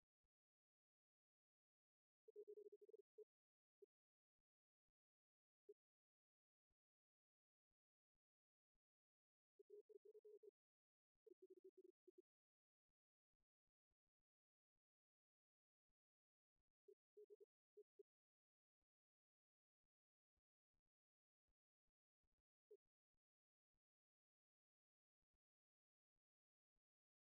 musique, ensemble musical
archives sonores réenregistrées
Pièce musicale inédite